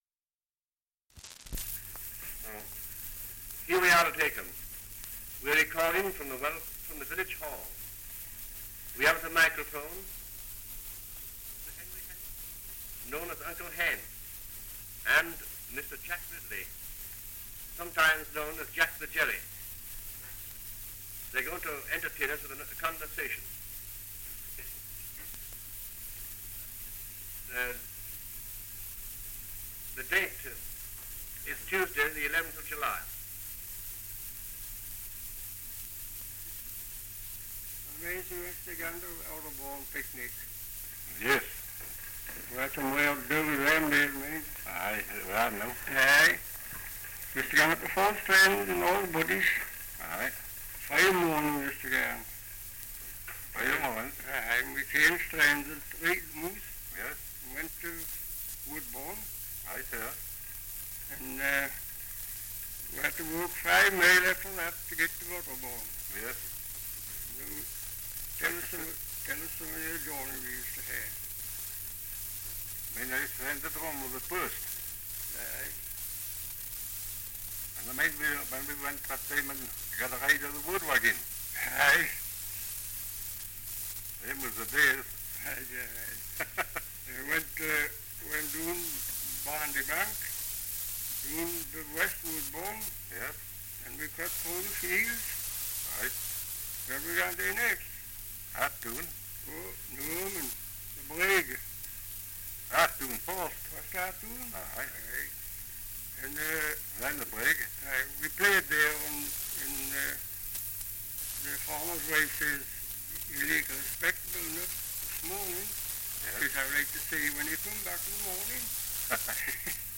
Dialect recording in Acomb, Northumberland
78 r.p.m., cellulose nitrate on aluminium